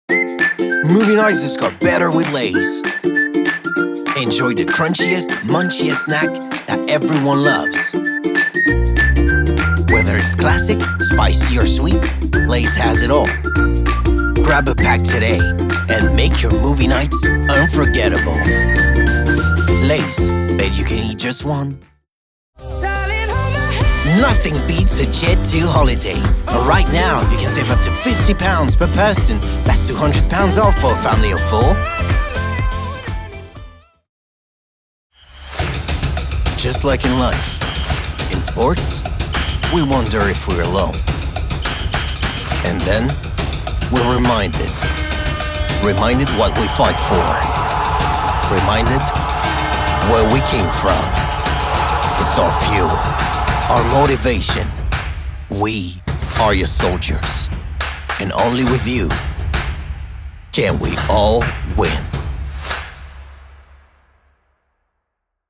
Voix off
25 - 40 ans - Baryton